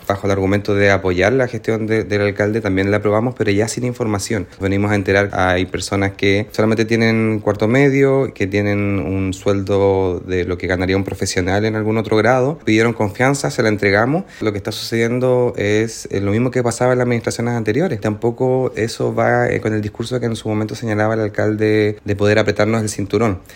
concejal-seba-almonacid.mp3